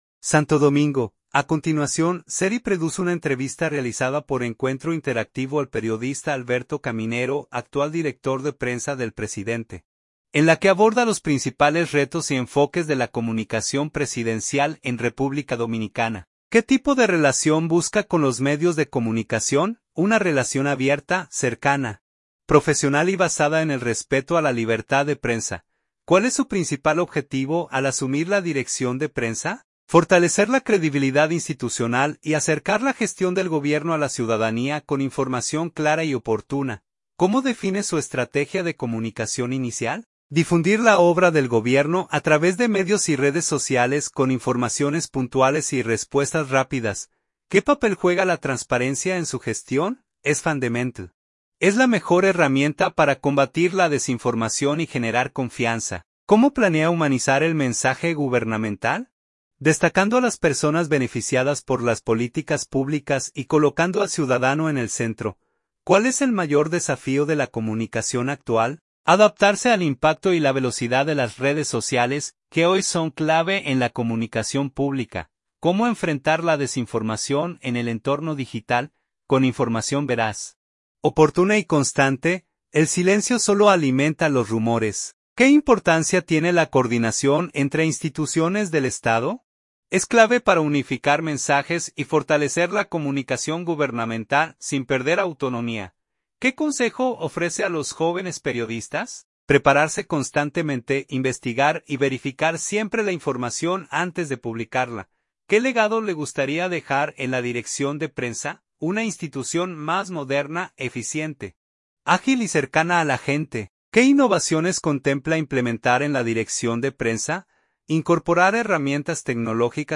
Santo Domingo.-A continuación, se reproduce una entrevista realizada por Encuentro Interactivo al periodista Alberto Caminero, actual director de Prensa del presidente, en la que aborda los principales retos y enfoques de la comunicación presidencial en República Dominicana